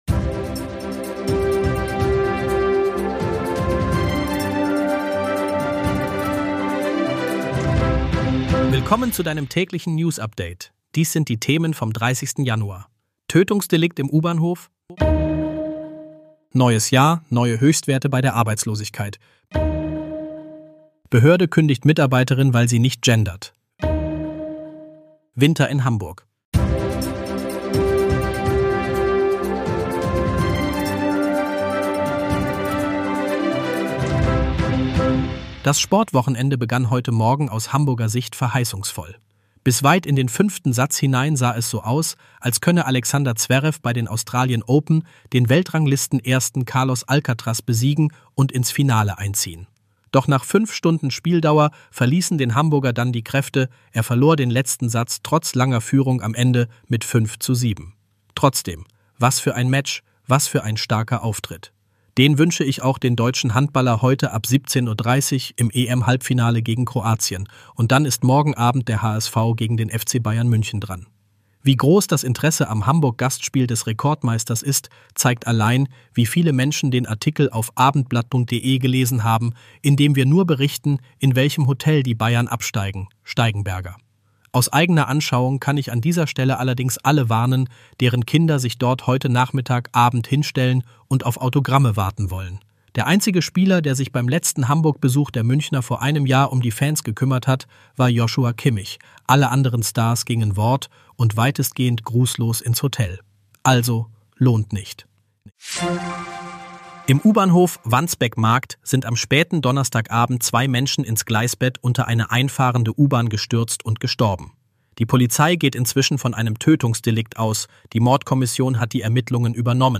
Hamburg-News - der aktuelle Nachrichten-Überblick um 17 h